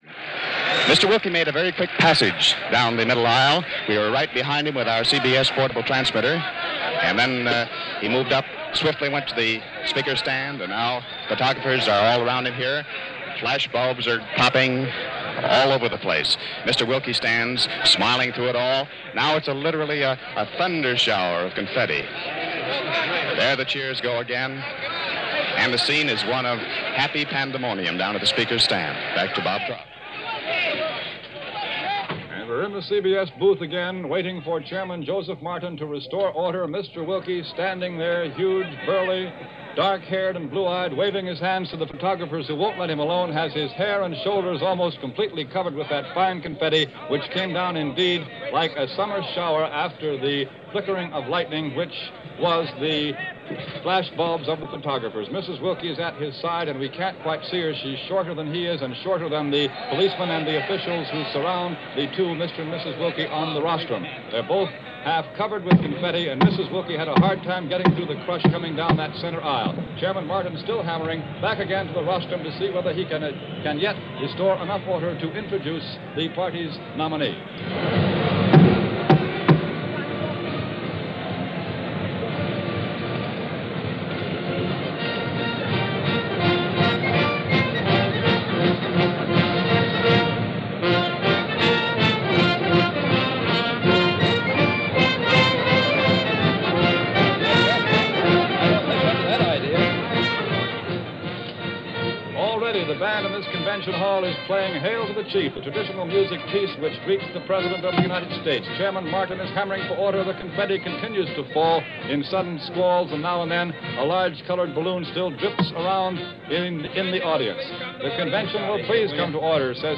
Republican Convention 1940 - Wendell Wilkie Acceptance Speech - Past Daily Reference Room
His speech lasted just a little over six minutes – the demonstration lasted a bit longer.
Here is that address as broadcast by CBS Radio on the closing night of the 1940 Republican convention.
willkie-acceptance-speech-1940.mp3